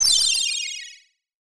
snd_magicsprinkle.wav